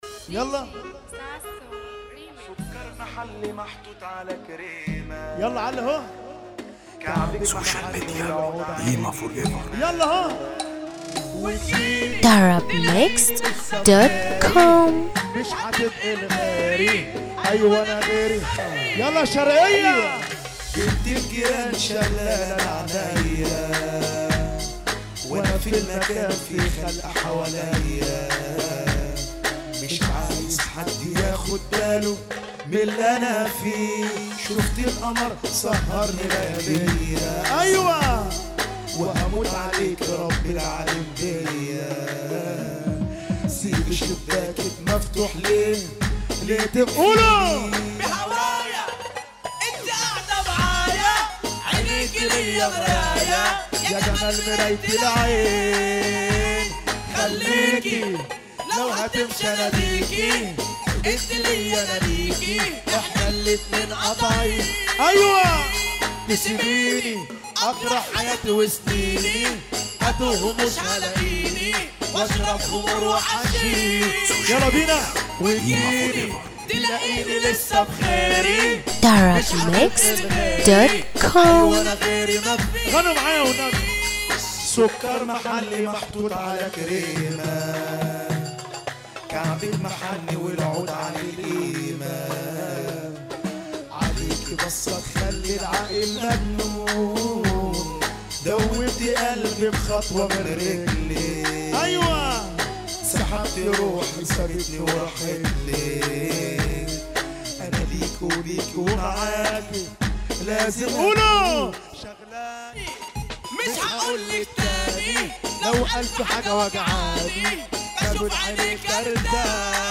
موال